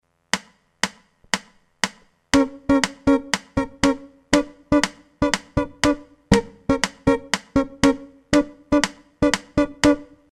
la CLAVE di Son si distingue da quella di Rumba (Rumba Guaguanco, per la precisione) per una sola nota, sia nel caso in cui vengano suonate 3-2 [